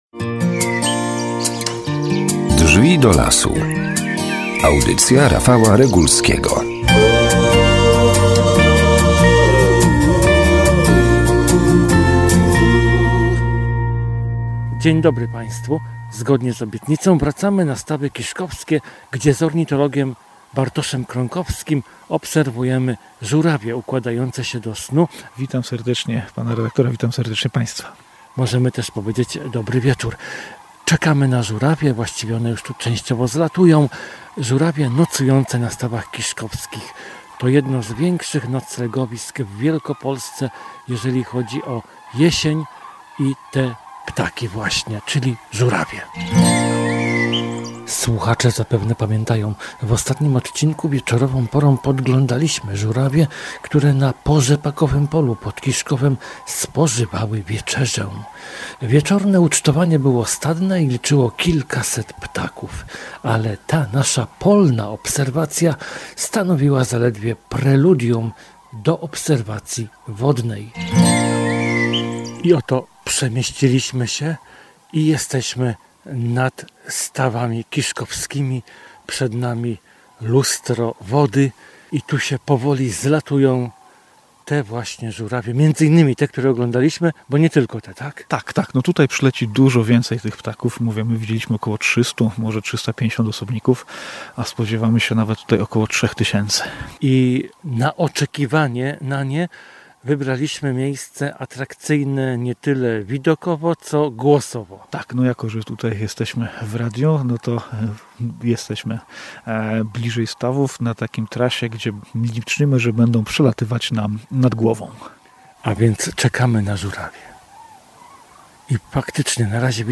Tydzień temu żurawie podglądaliśmy z oddalenia. Dzisiaj - polecą nam one nad głowami.